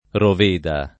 Roveda [ rov % da ]